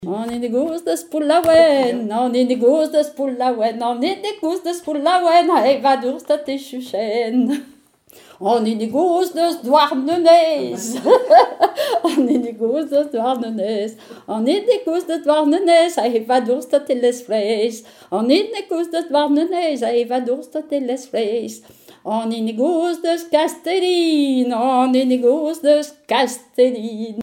Mémoires et Patrimoines vivants - RaddO est une base de données d'archives iconographiques et sonores.
témoignages et bribes de chansons
Pièce musicale inédite